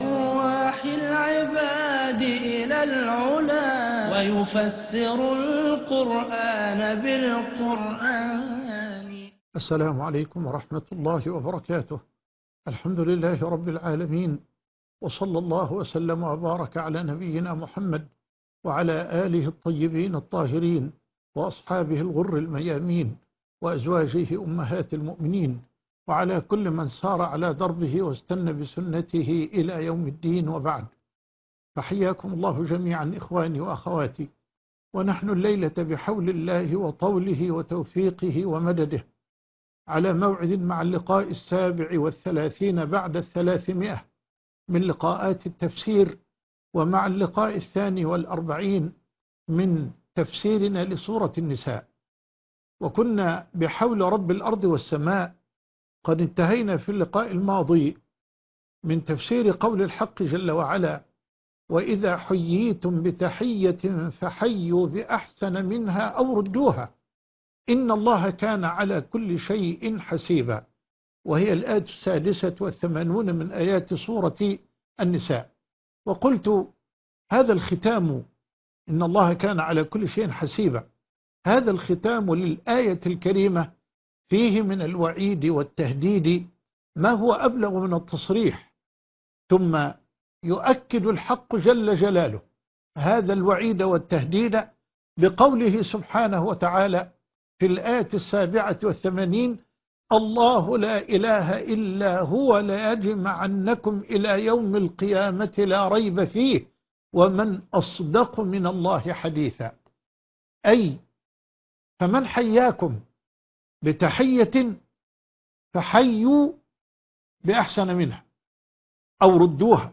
اللقاء 337 تفسير سورة النساء - فما لكم فى المنافقين فئتين (7/9/2023) التفسير - فضيلة الشيخ محمد حسان